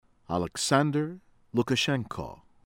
LEBEDKO, ANATOLY ah-nah-TOH-lee   leh-BEHD-koh